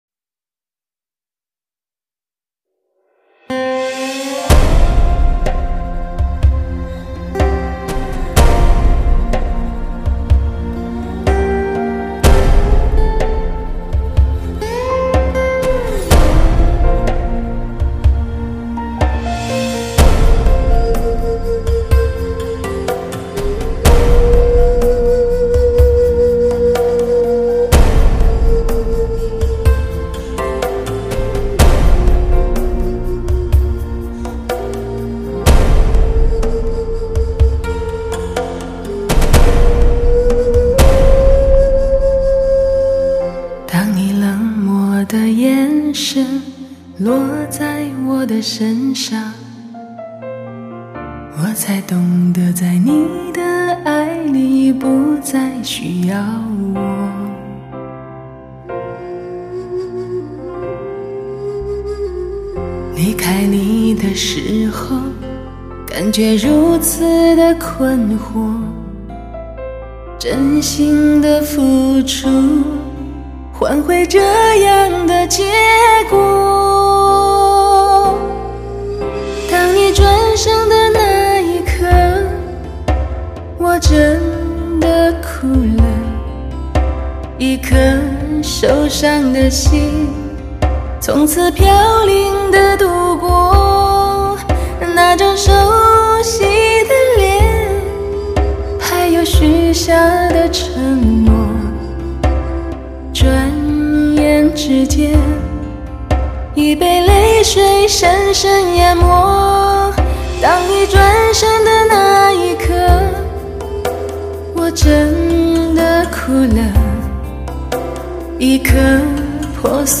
发烧演绎 超靓音效2010流行发烧唱片
透过人声连接心与心的距离
为人们呈现最真实的原音 表现最诚挚的感情